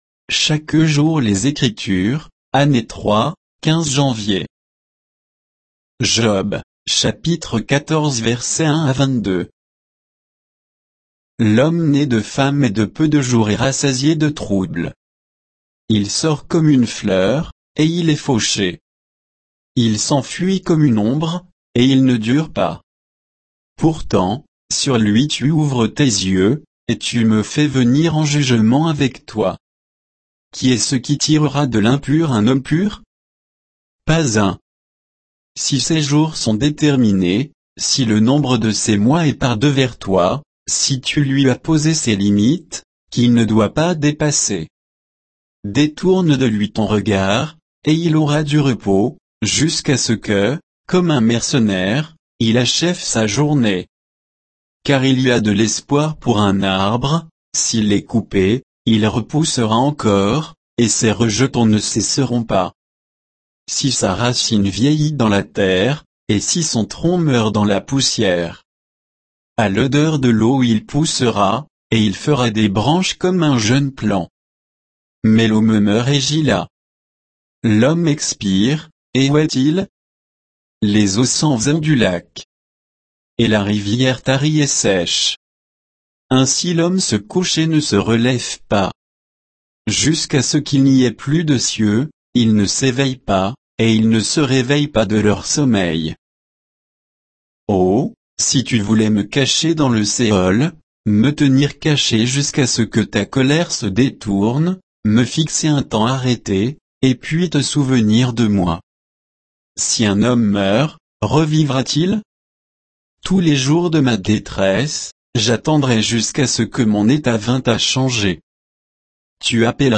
Méditation quoditienne de Chaque jour les Écritures sur Job 14